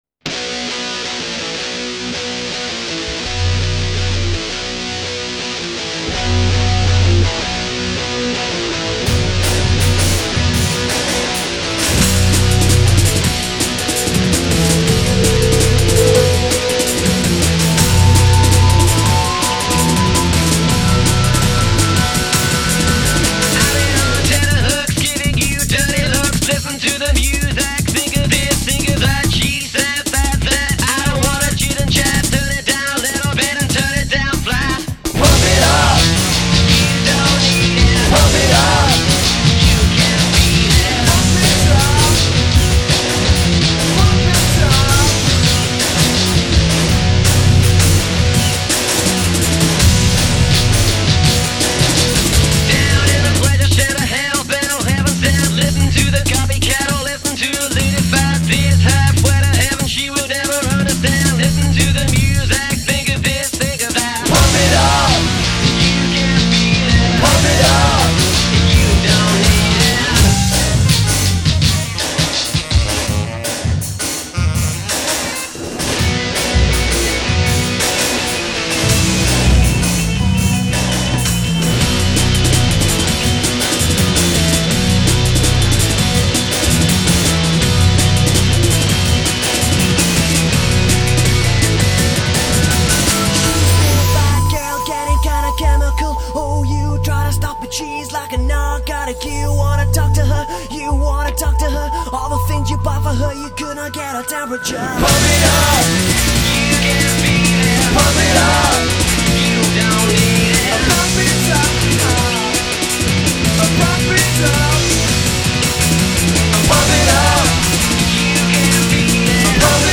Some of the dirtiest guitars on record.